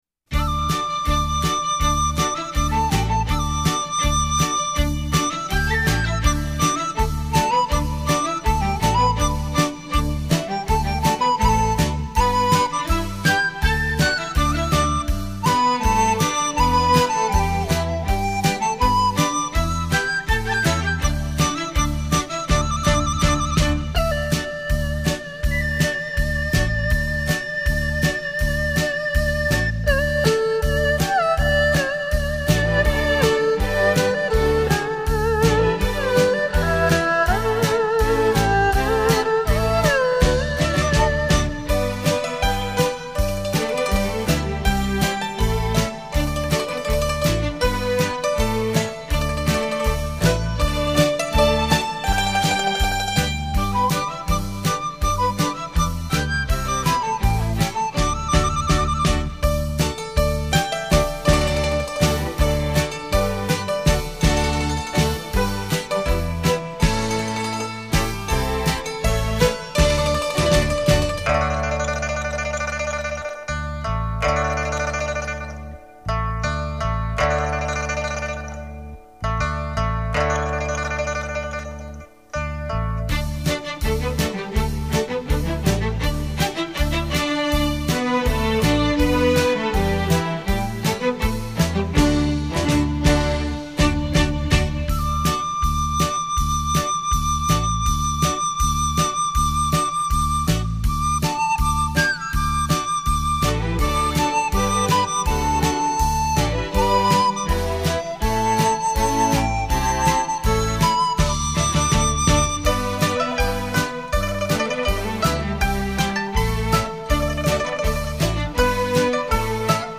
錄音室：佳聲錄音室